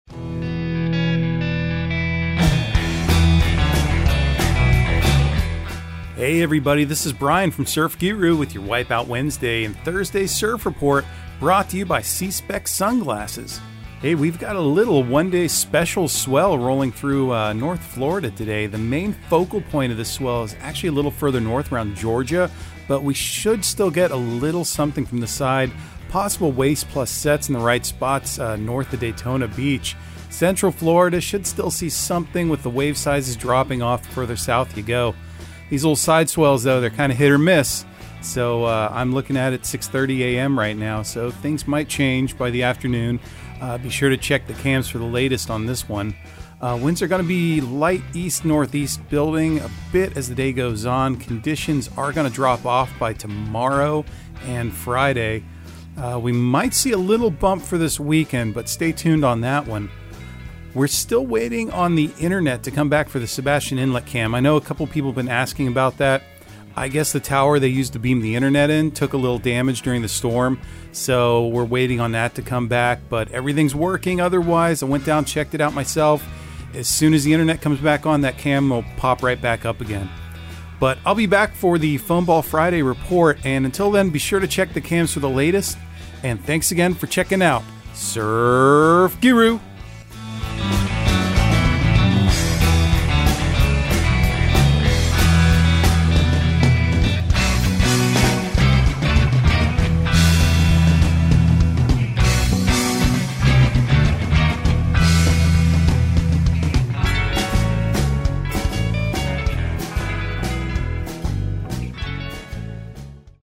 Surf Guru Surf Report and Forecast 10/12/2022 Audio surf report and surf forecast on October 12 for Central Florida and the Southeast.